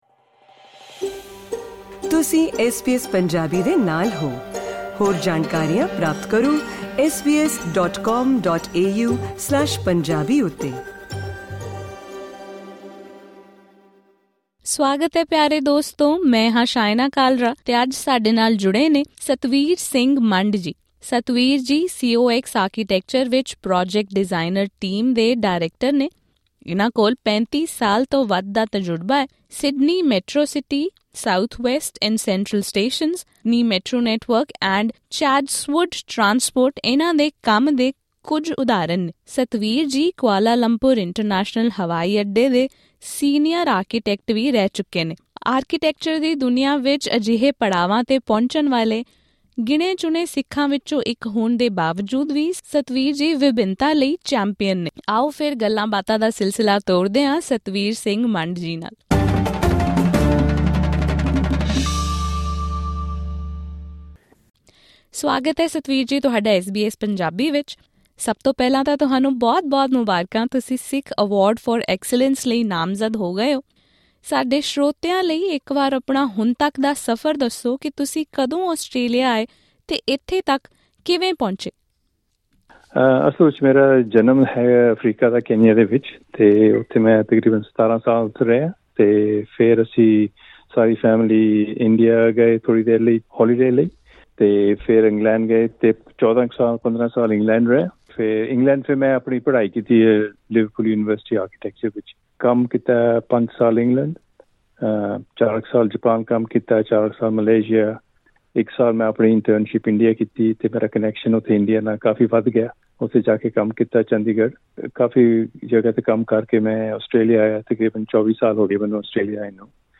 ਉਹ ਦੁਨੀਆ ਭਰ ਦੇ ਪ੍ਰੋਜੈਕਟਾਂ 'ਤੇ ਕੰਮ ਕਰ ਚੁੱਕੇ ਨੇ ਪਰ ਹਜੇ ਵੀ ਉਹ ਮਹਿਸੂਸ ਕਰਦੇ ਹਨ ਕਿ ਭਾਈਚਾਰੇ ਵਿੱਚ ਕਲਾ ਨਾਲ ਸੰਬੰਧਿਤ ਪੇਸ਼ਿਆਂ ਨੂੰ ਅਪਣਾਉਣ ਦੀ ਲੋੜ ਹੈ। ਇਸ ਪੋਡਕਾਸਟ ਰਾਹੀਂ ਉਨ੍ਹਾਂ ਨਾਲ ਕੀਤੀ ਪੂਰੀ ਗੱਲਬਾਤ ਸੁਣੋ....